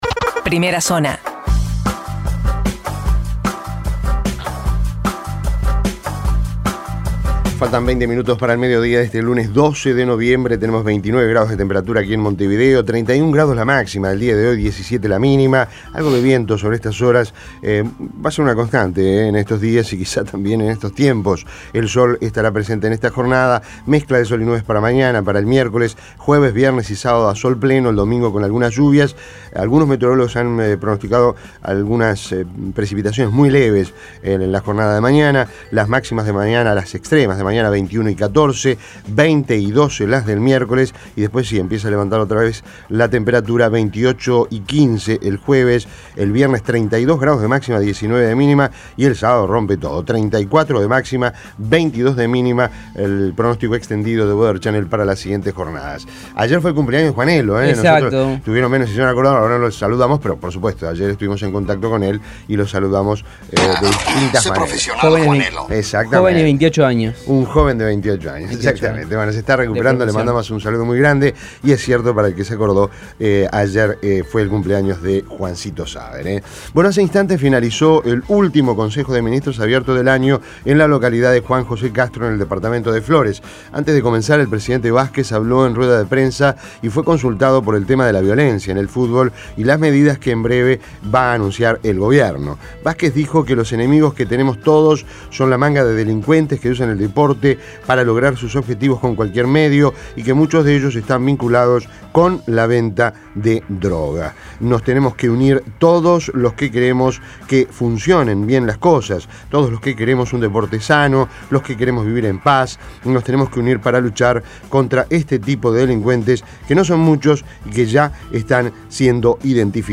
Resumen de noticias Primera Zona Imprimir A- A A+ Las principales noticias del día, resumidas en la Primera Zona de Rompkbzas.